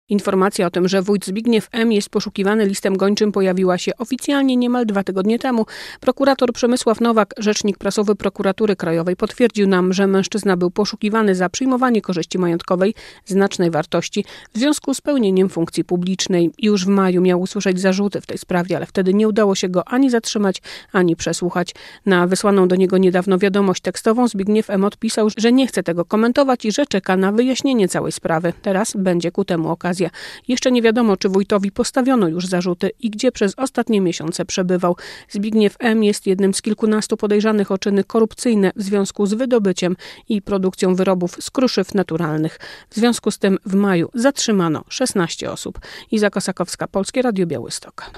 Wójt gminy Suwałki zatrzymany - korespondencja